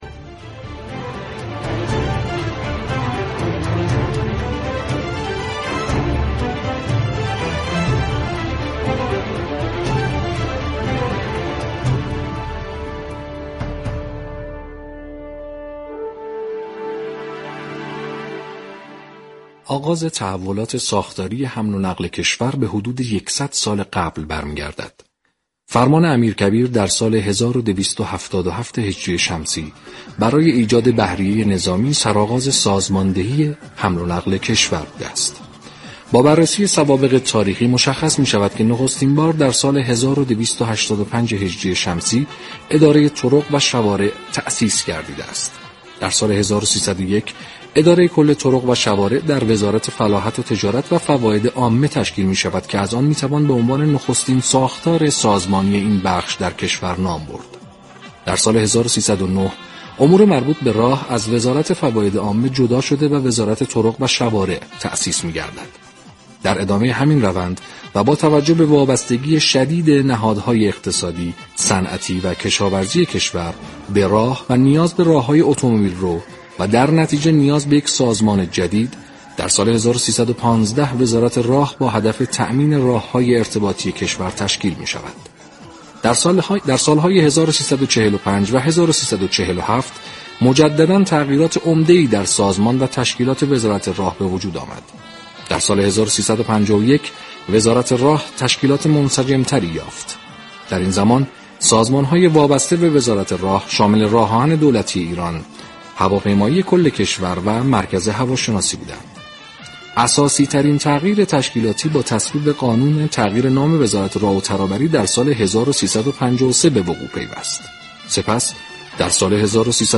به گزارش پایگاه اطلاع رسانی رادیو تهران، عارف امرایی معاون راهداری و حمل و نقل جاده‌ای استان تهران در گفت و گو با برنامه «پل مدیریت» 26 دی در توضیح جاده‌ها و محورهای مواصلاتی استان تهران گفت: استان تهران دارای 3 هزار و 96 كیلومتر راه؛ شامل 329 كیلومتر آزادراه، 455 كیلومتر بزرگراه، 231 كیلومتر راه اصلی، 2800 كیلومتر راه فرعی و روستایی، 2 هزار و 996 پل و 54 تونل به طول 40 كیلومتر است.